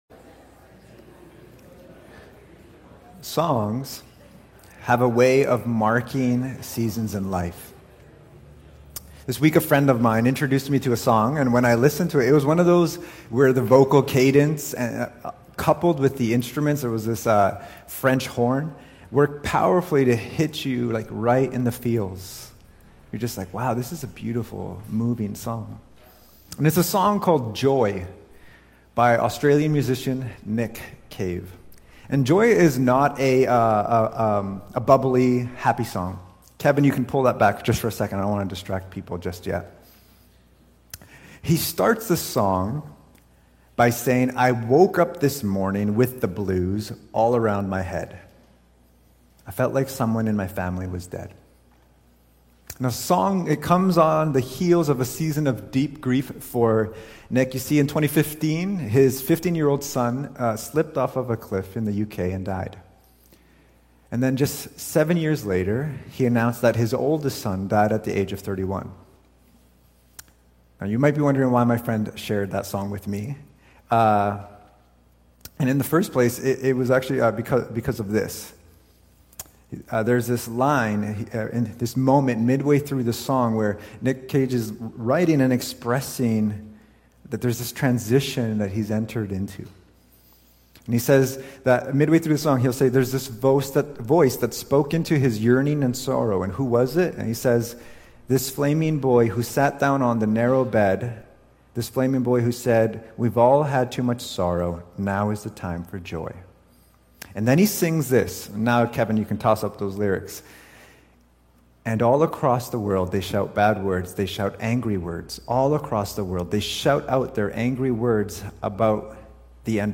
Cascades Church Sermons